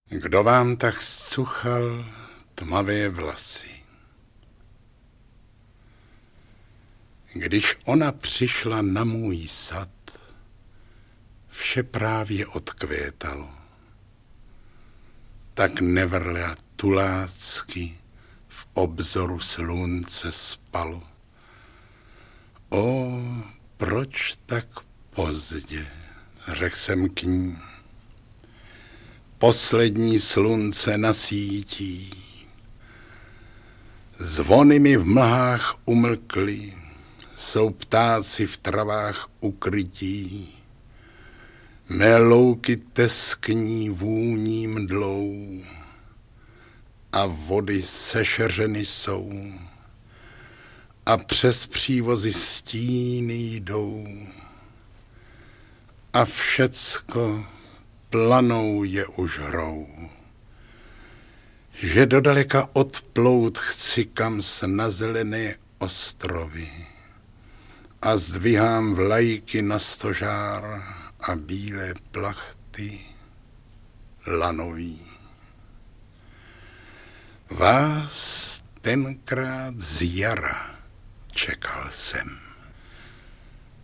Interpret:  Zdeněk Štěpánek
AudioKniha ke stažení, 4 x mp3, délka 14 min., velikost 12,6 MB, česky